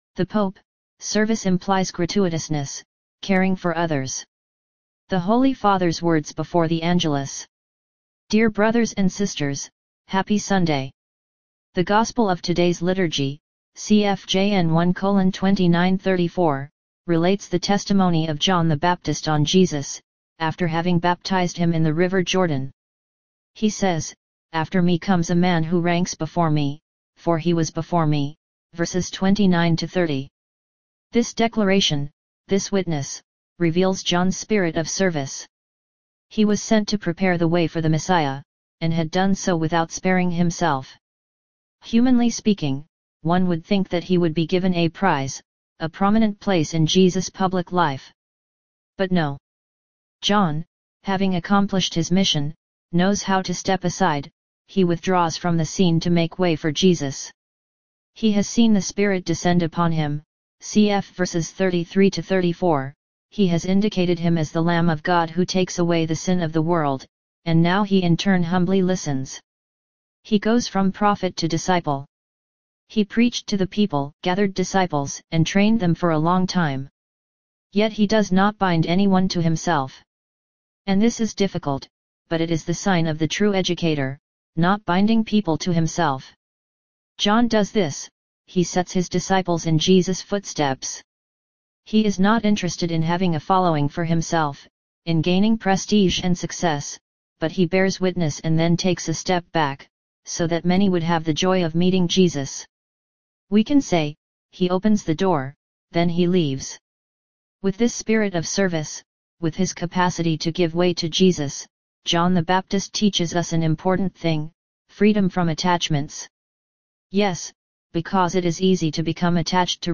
The Holy Father’s words before the Angelus